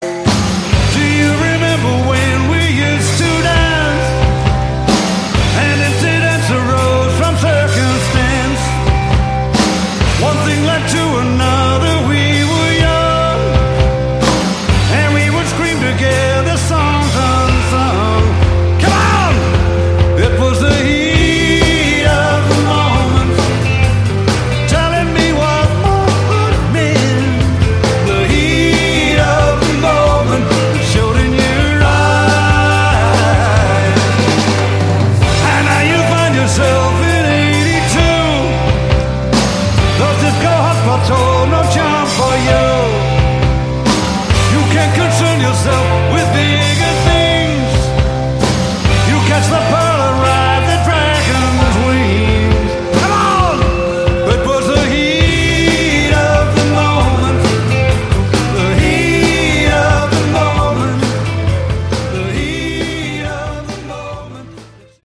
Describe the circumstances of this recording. Recorded live 2009 in Cambridge, UK